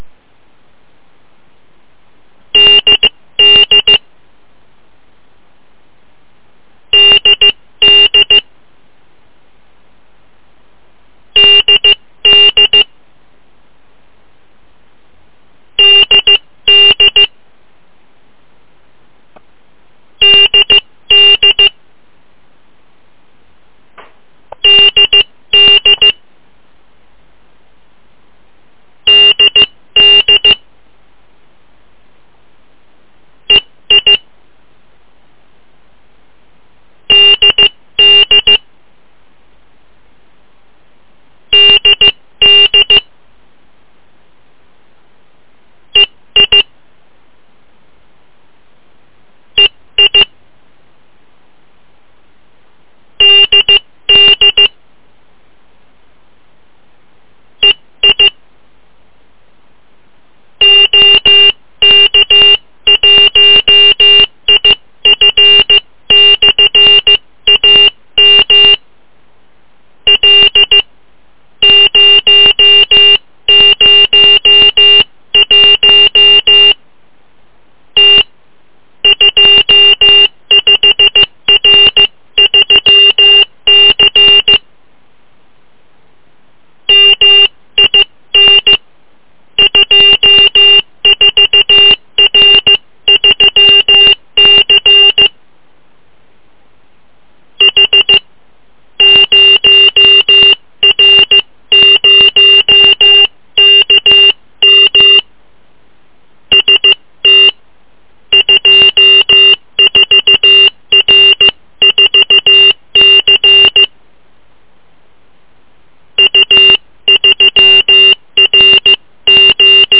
Sound of demo telemetry.
Zde je Demo zvuk vysílače Sputniku 3.
Mimo vysílání telemetrie jsou slyšet zvuky teplotního Vária.
Telemetrie se vysílá přibližně jednou za 2 minuty.
Sputnik3_soundDemo.mp3